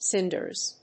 発音記号
• / ˈsɪndɝz(米国英語)
• / ˈsɪndɜ:z(英国英語)